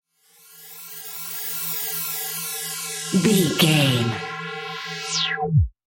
Bright Sweeper Disappear
Sound Effects
funny
magical
mystical